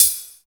39 HAT 4  -R.wav